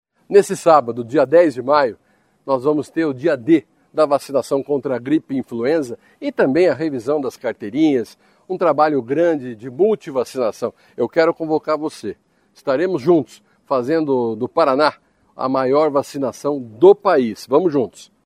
Sonora do secretário Estadual da Saúde, Beto Preto, sobre o Dia D de Multivacinação